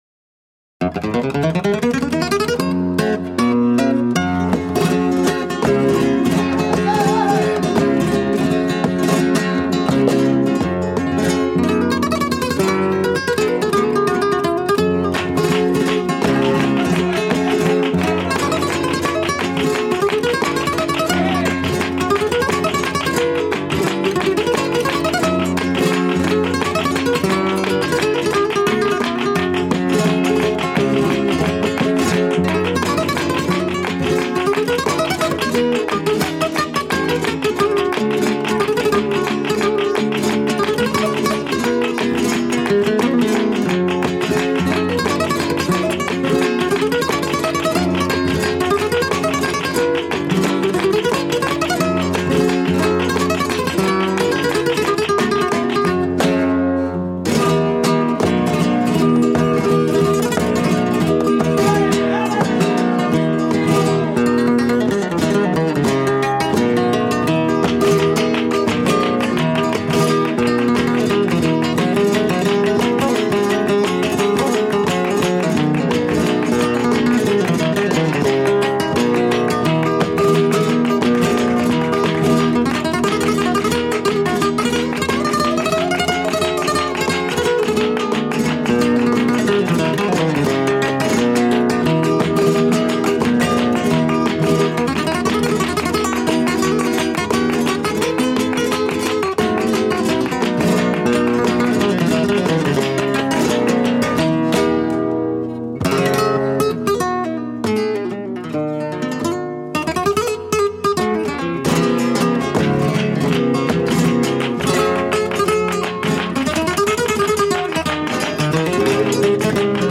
Sevillanas